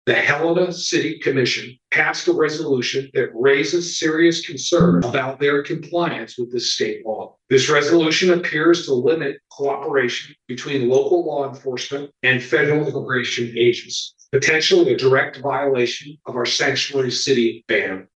Governor Greg Gianforte held a joint press conference with Attorney General Austin Knudsen, raising concerns the city of Helena could be violating state law by its refusal to cooperate with federal immigration officials.